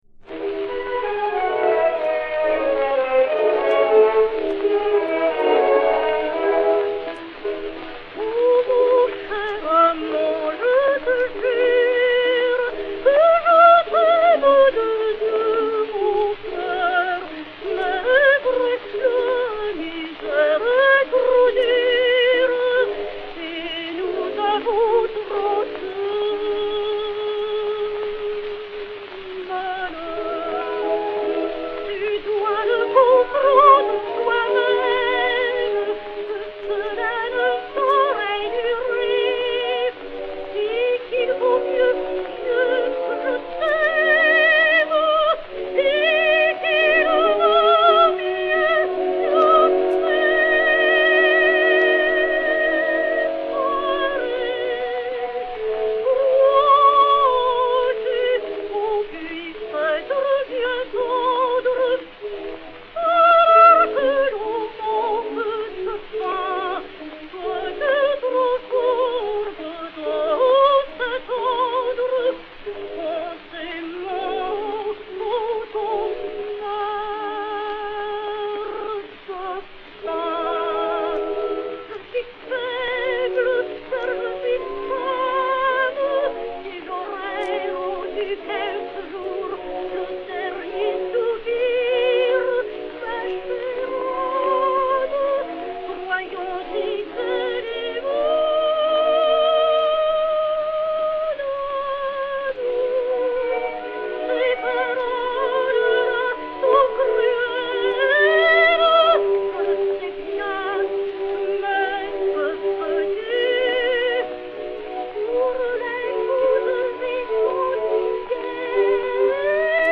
Emma Calvé (la Périchole) et Orchestre
Pathé saphir 0289, mat. 2349, enr. Paris juin/juillet 1920